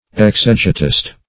Search Result for " exegetist" : The Collaborative International Dictionary of English v.0.48: Exegetist \Ex`e*ge"tist\, n. One versed in the science of exegesis or interpretation; -- also called exegete .